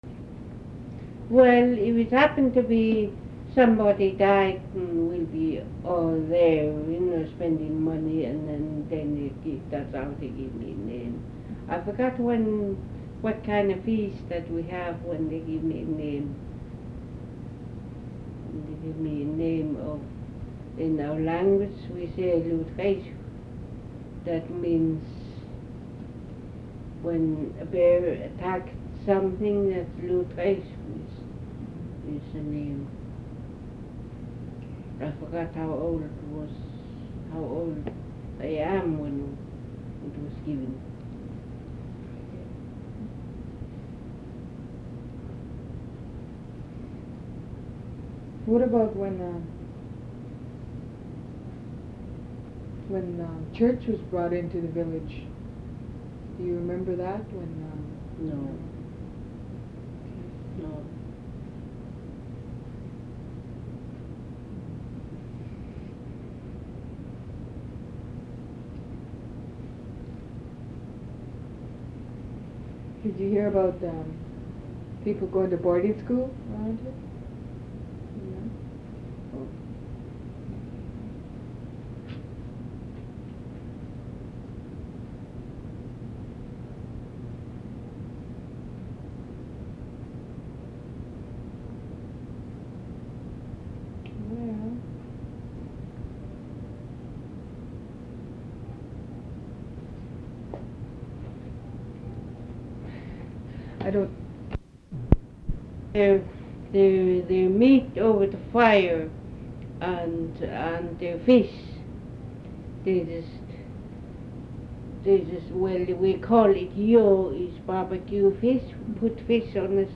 Audio non-musical
oral histories (literary works)